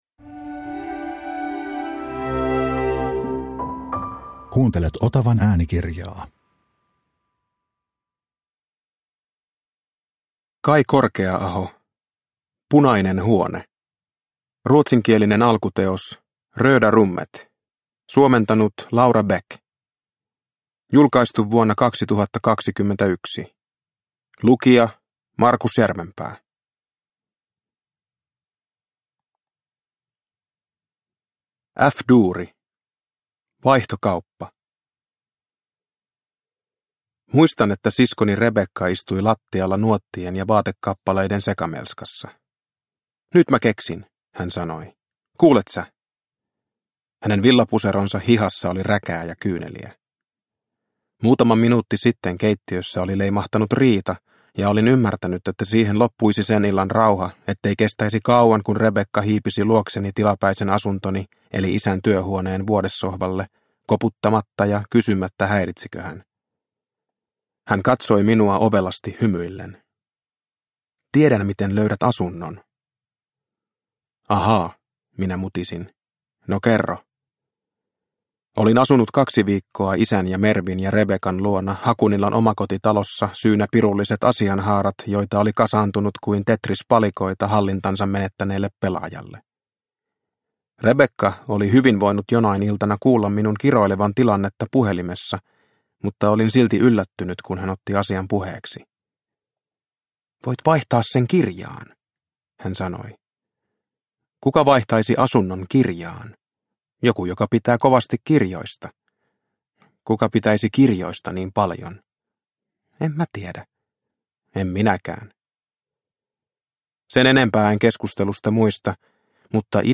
Punainen huone – Ljudbok – Laddas ner